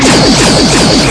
Torpedo Fire